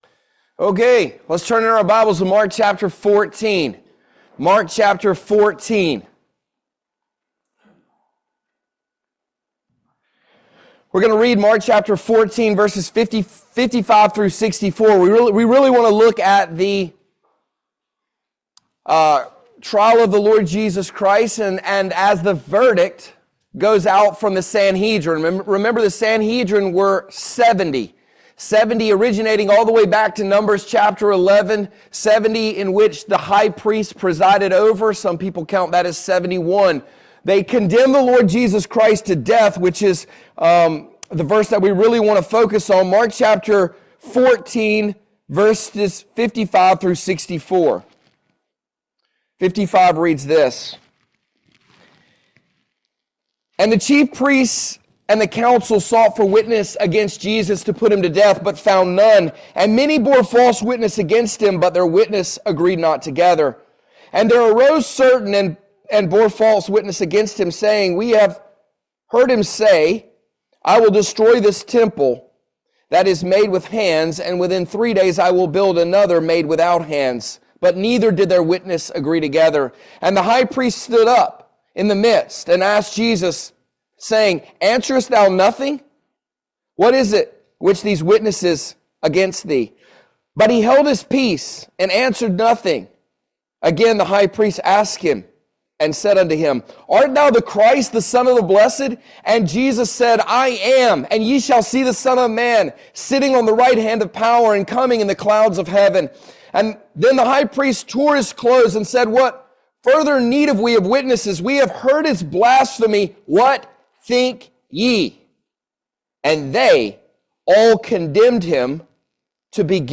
Mark 14:55-64 Service Type: Family Bible Hour Are we fully submitted to the authority of God in our lives?